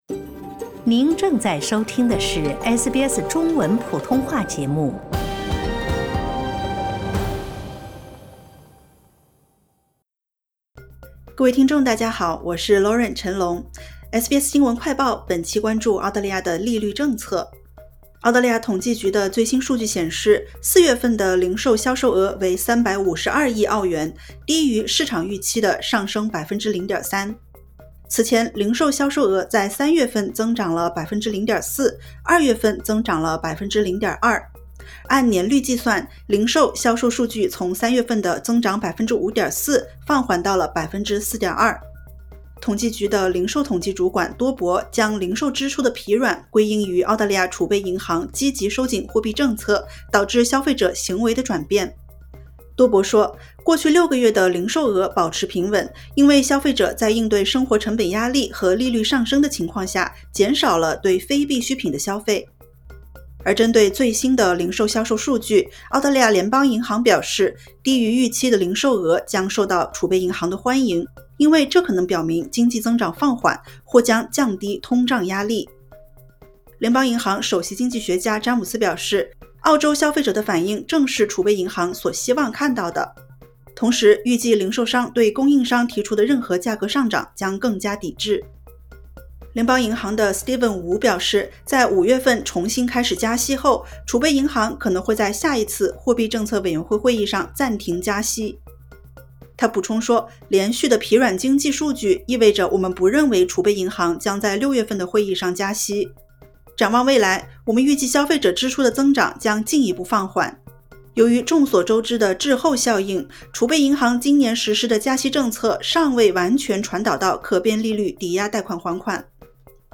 【SBS新闻快报】经济指标弱于预期 CBA称利率将在6月暂停上涨